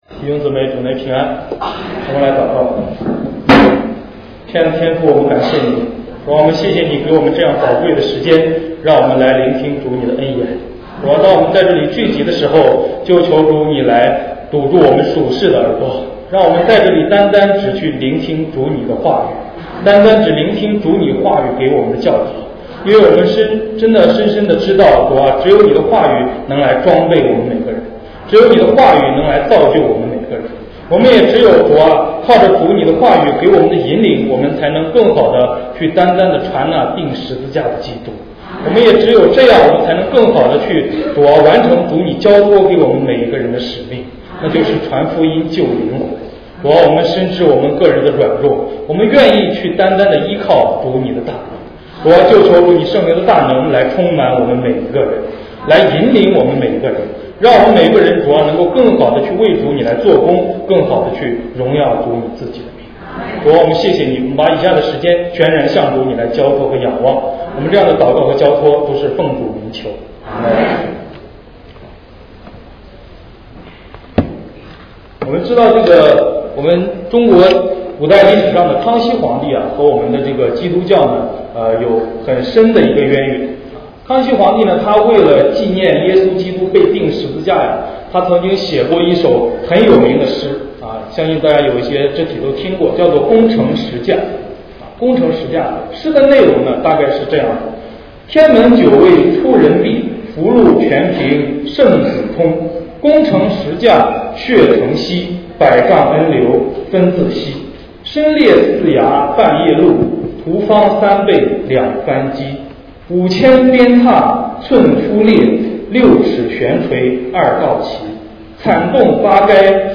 主日证道-音频